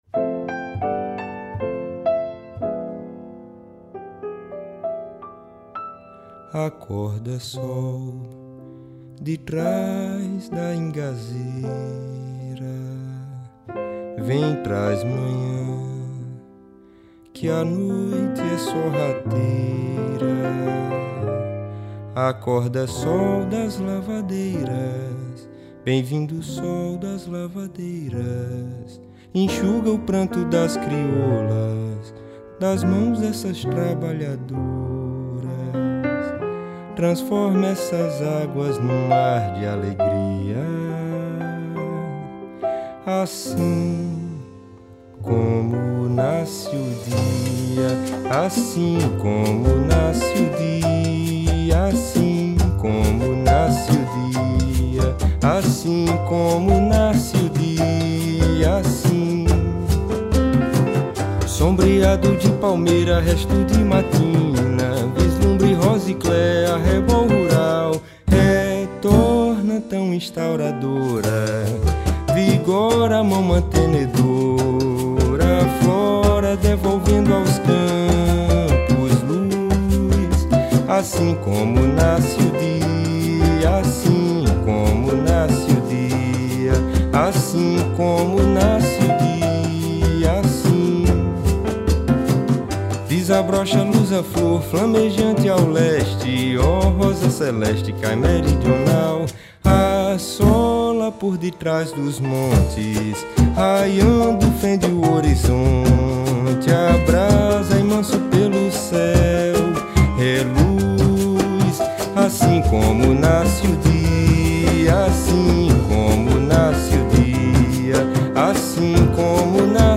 piano e voz
flauta
congas e vocal
cobel, abê, prato e vocal